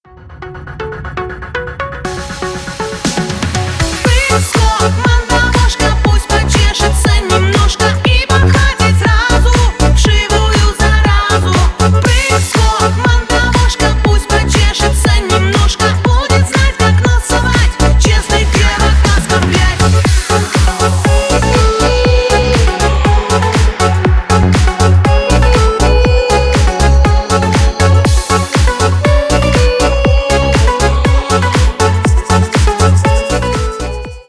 Шансон
весёлые